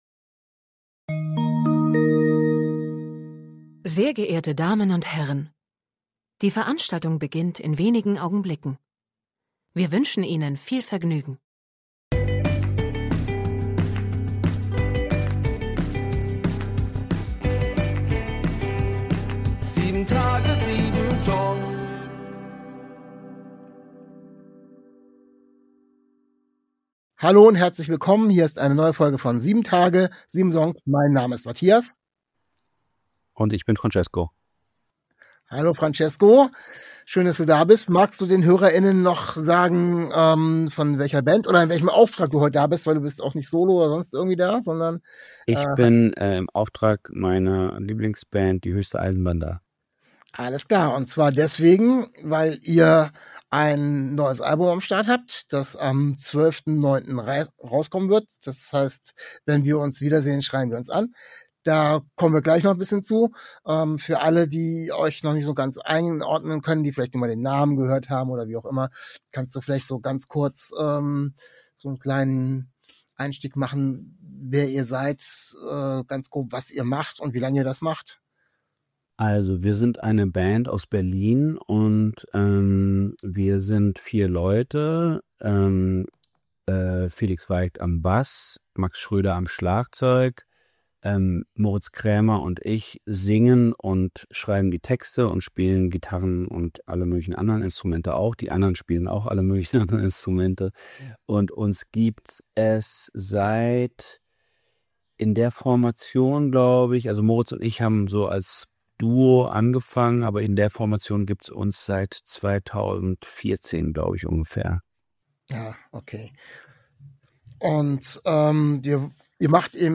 Zu Gast im Interview